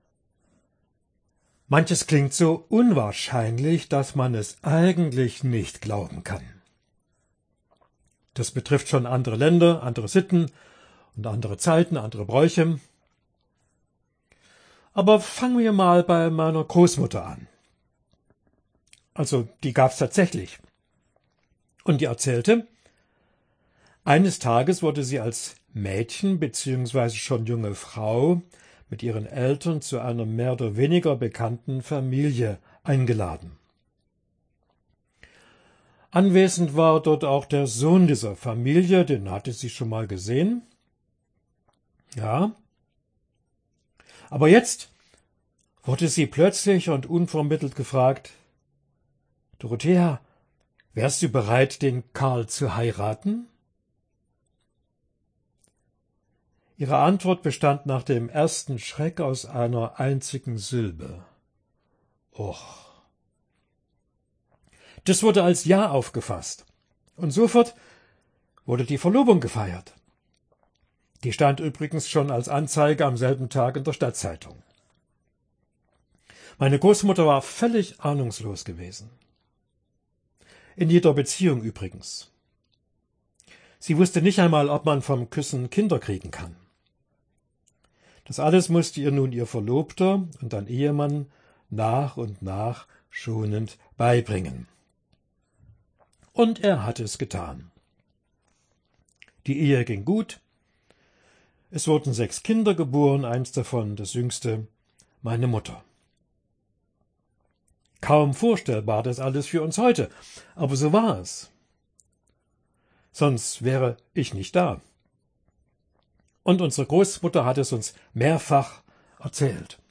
Dezember 2025 Heruntergeladen 408 Mal Kategorie Audiodateien Vorträge Schlagwörter Weihnachten , Wissenschaft , maria , lukas 1 , jungfrauengeburt , Matthäus 1 , Jungfrau Beschreibung: Eine Annäherung Hilfen zum Verstehen.